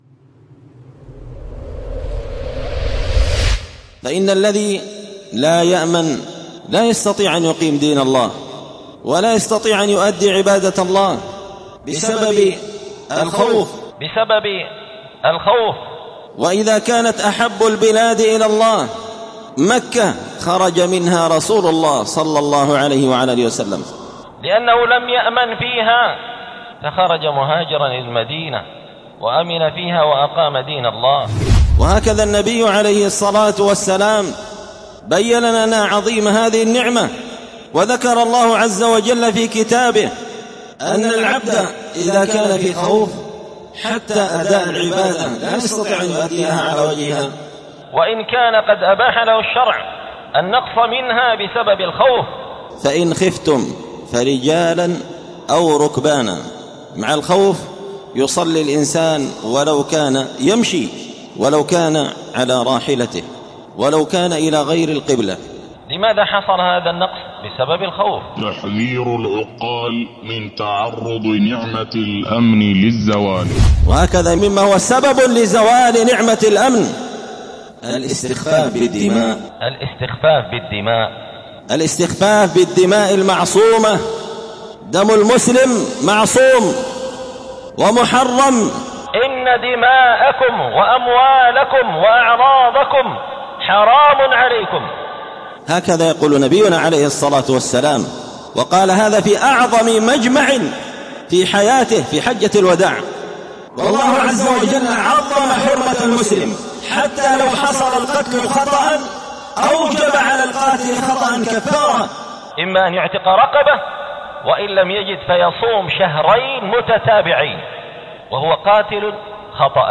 الجمعة 16 محرم 1447 هــــ | الخطب والمحاضرات والكلمات | شارك بتعليقك | 90 المشاهدات